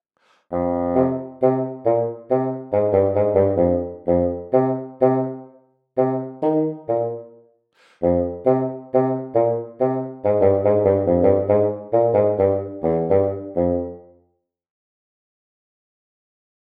Fagot
Dźwięki instrumentów są brzmieniem orientacyjnym, wygenerowanym w programach:
Fagot.mp3